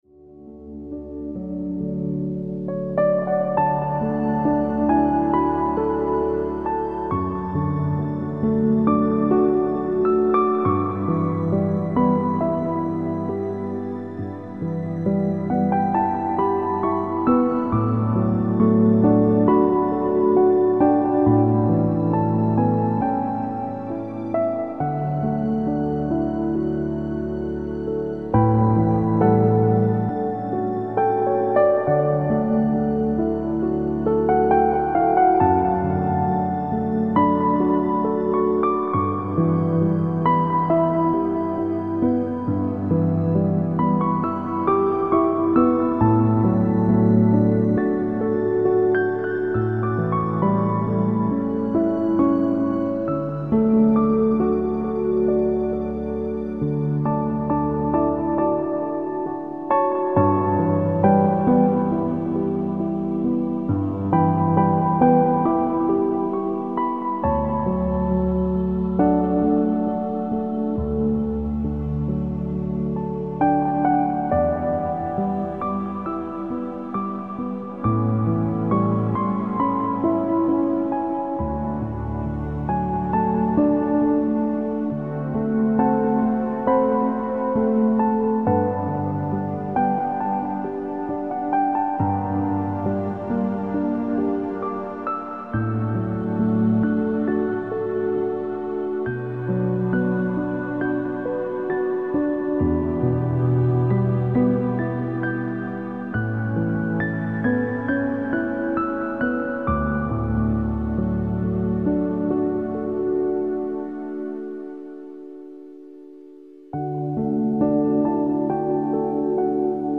Music for relaxation and reflection